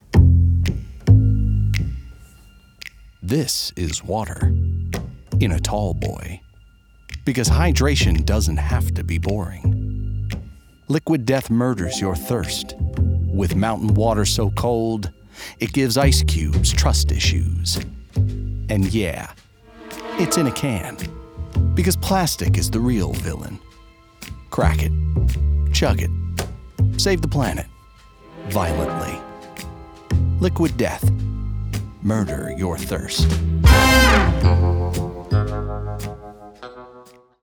Gritty · Bold · Comedic
Edgy, irreverent commercial read for disruptive brands. Big energy with authentic swagger.